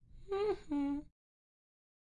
愚蠢的男性笑声 嘿嘿，笑声
描述：一个男人轻笑。
标签： 语音 表演 演员 过度 幽默 搞笑
声道立体声